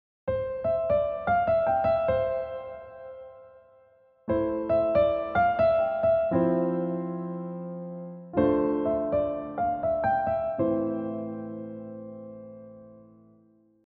We’ve played a very recognisable melody in a C major key, then repeated it over a simple chord progression in C major’s relative minor key, A minor. This completely changes the context and feel of the original melody: